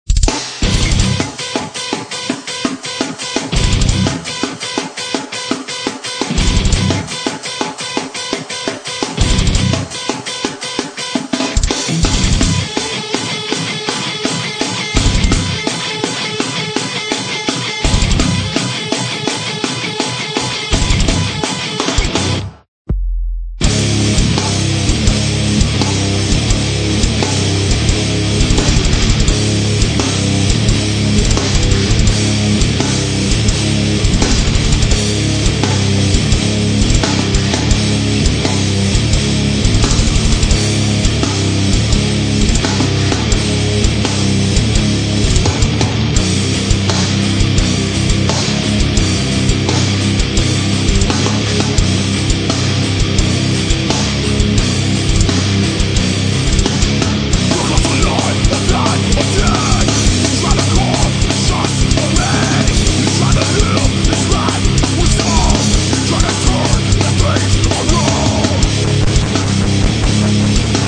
Recorded at Music Lab Studios, Austin, Tx.